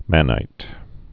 (mănīt)